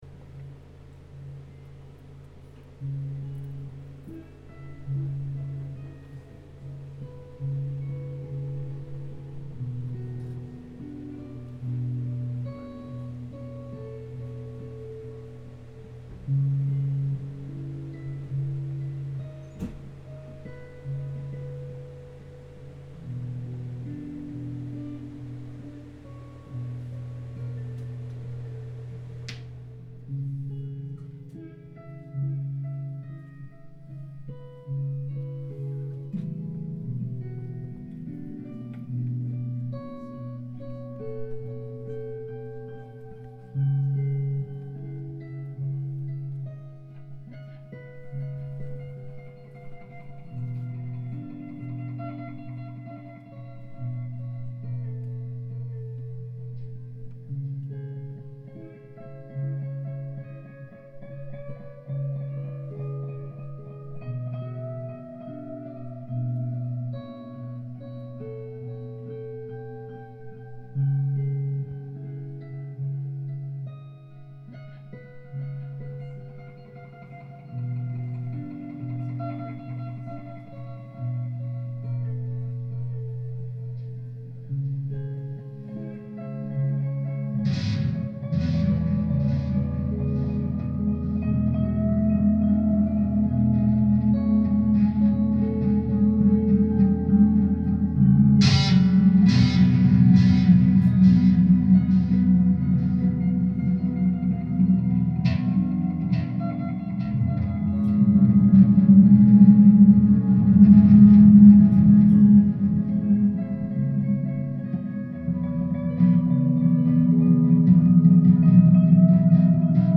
at Hanger 18.